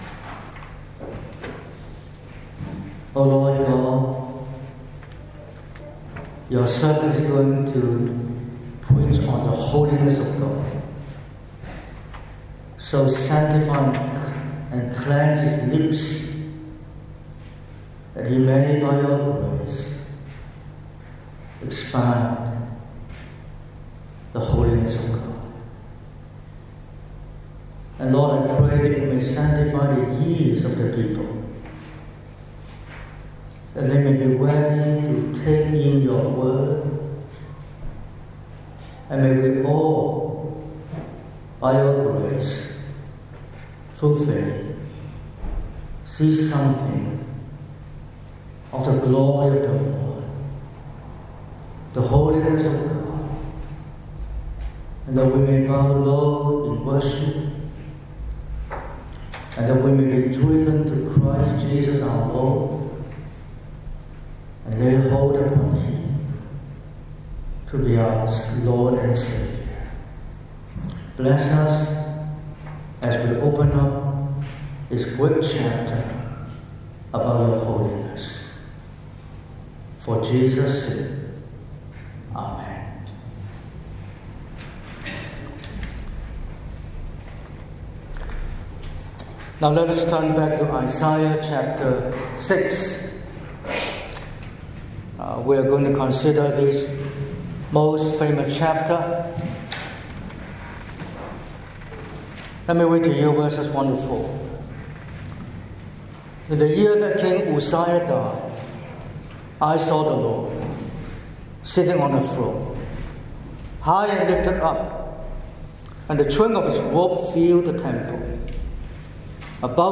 19/10/2025 – Morning Service: Holy, holy, holy